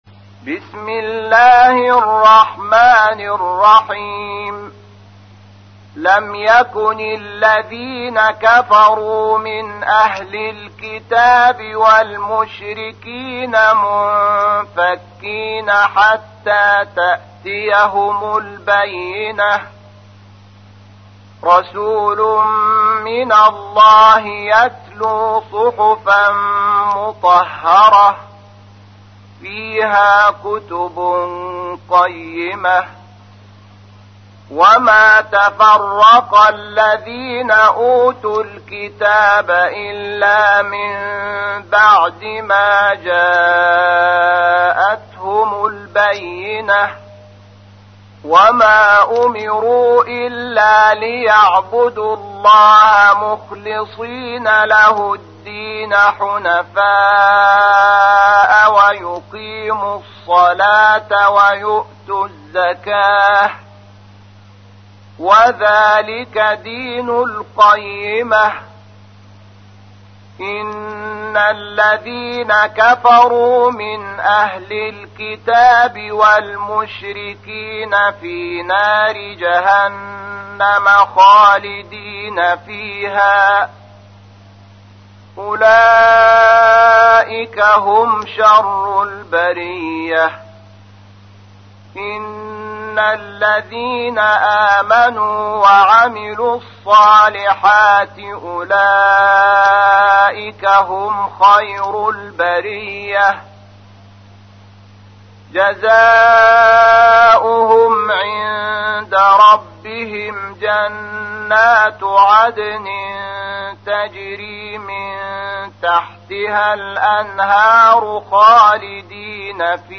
تحميل : 98. سورة البينة / القارئ شحات محمد انور / القرآن الكريم / موقع يا حسين